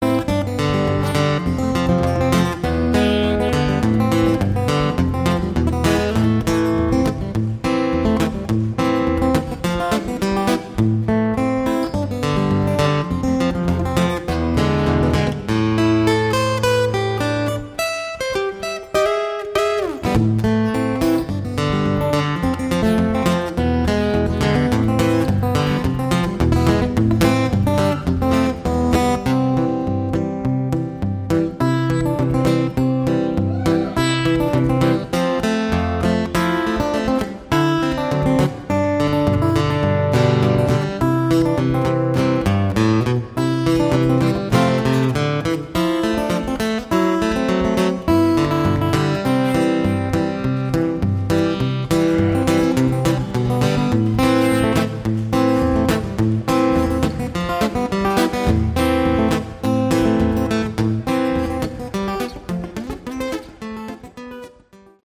Category: Prog Rock
bass, vocals
drums
guitars
keyboards